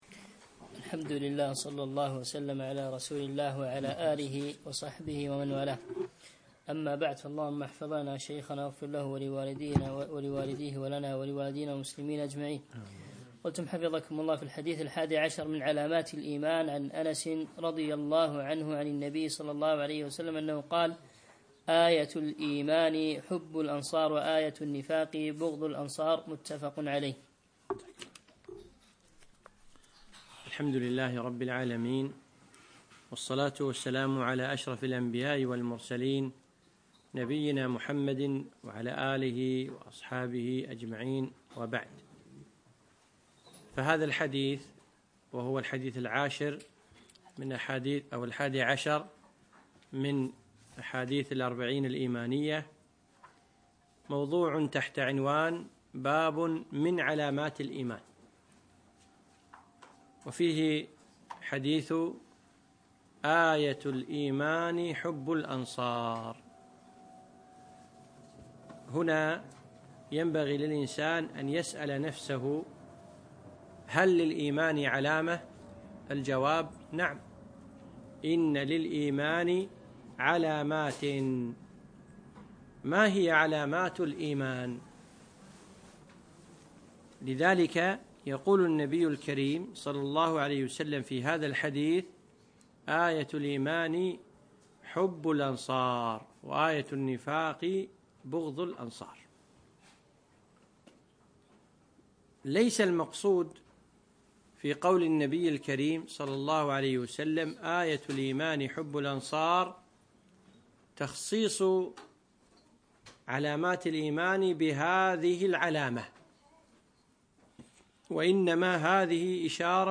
الدرس الحادي عشر